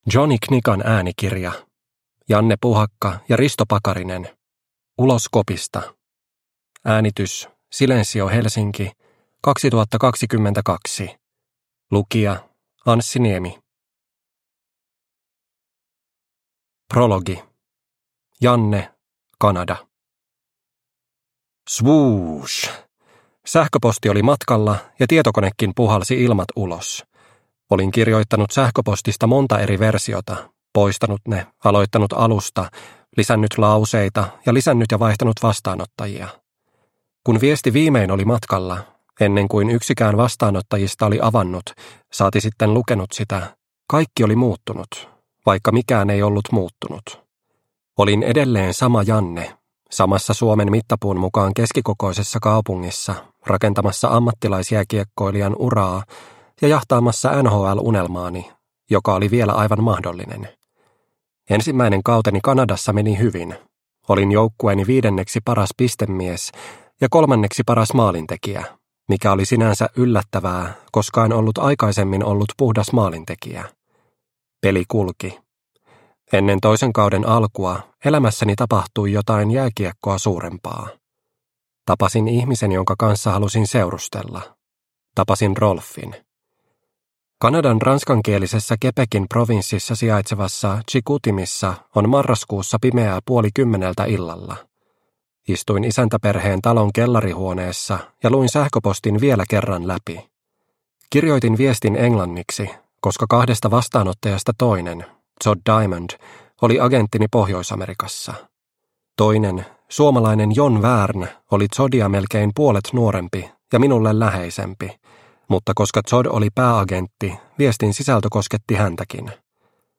Ulos kopista – Ljudbok – Laddas ner